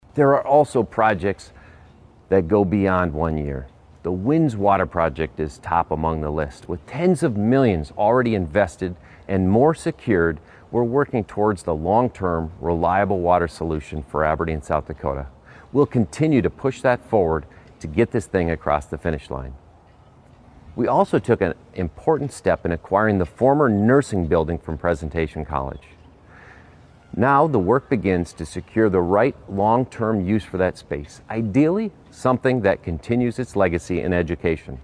The address was delivered at Storybook Land.